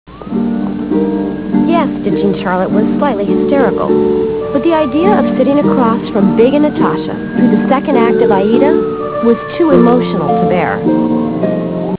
Classical/Instrumental
Comment: instrumental